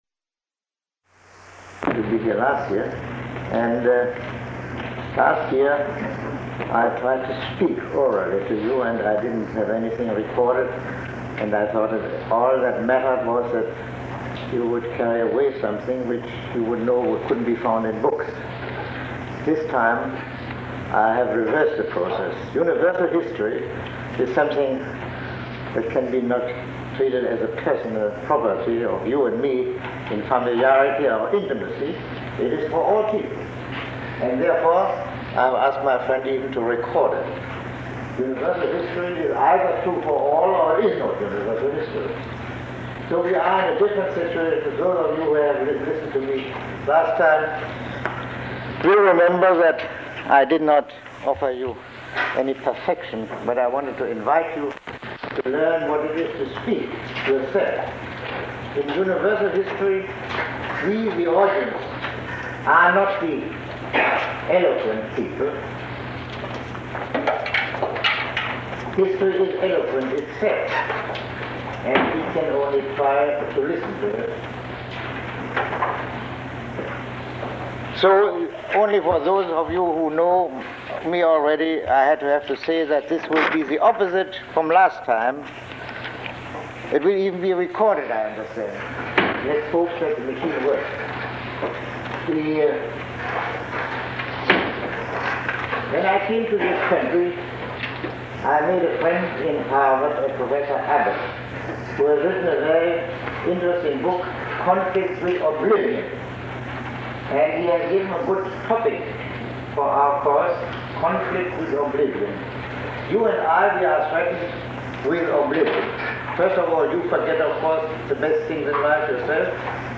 Lecture 01